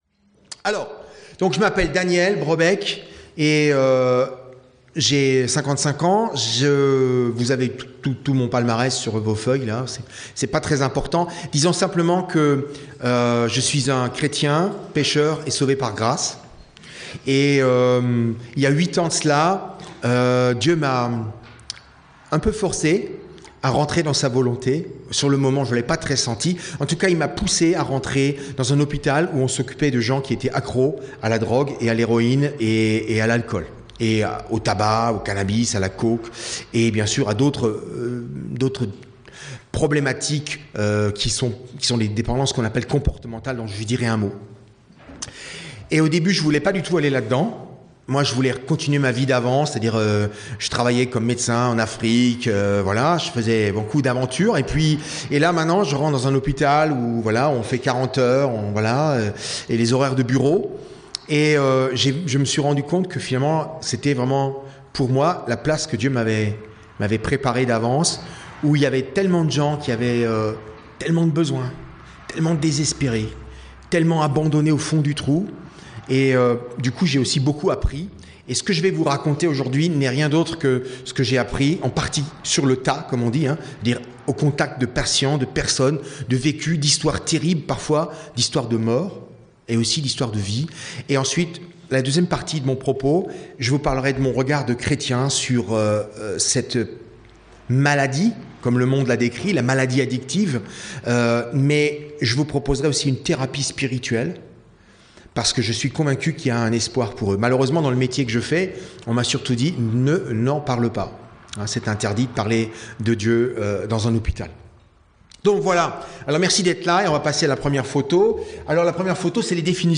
Pâques 2019 - Ateliers